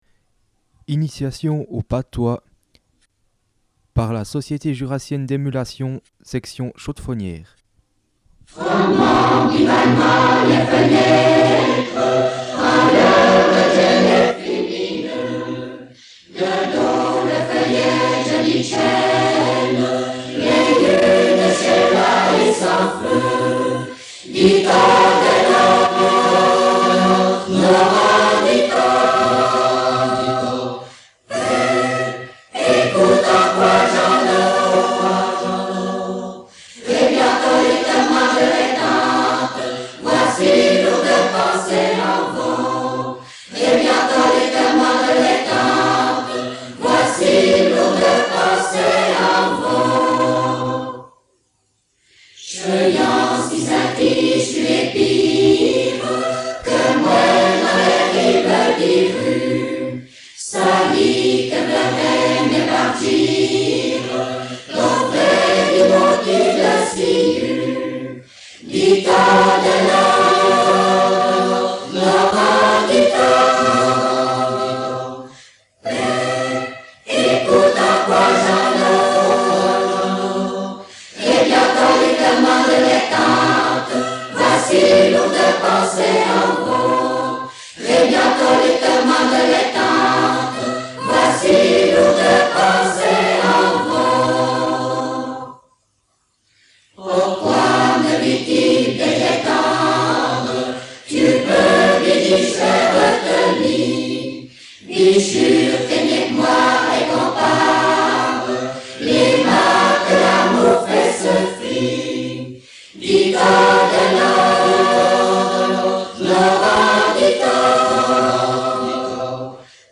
16 mars 2014 Initiation de la SJE Initiation de la SJE Initiations au patois enregistrées par la Société jurassienne d’émulation, section chaux-de-fonnière.
02_Initiation_au_patois_2.mp3